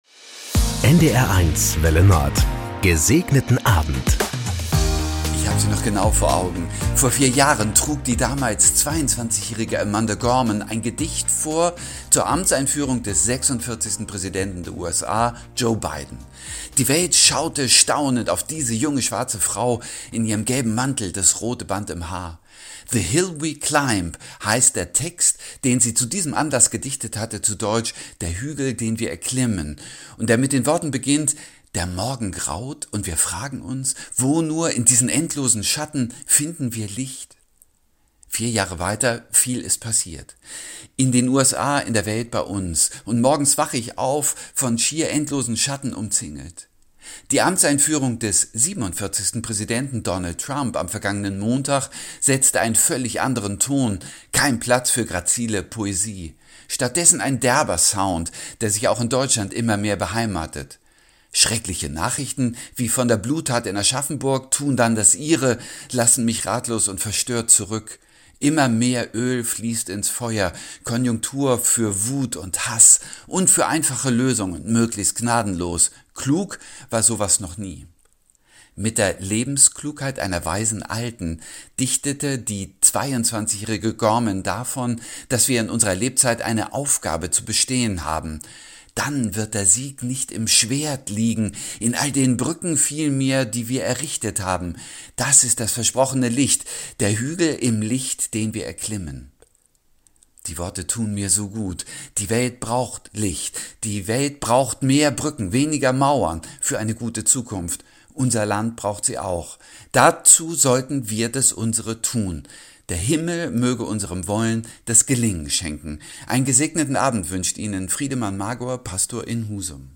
Das gute Wort zum Feierabend auf NDR 1 Welle Nord mit den Wünschen für einen "Gesegneten Abend". Von Sylt oder Tönning, Kiel oder Amrum kommt die Andacht als harmonischer Tagesabschluss.